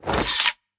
holster1.ogg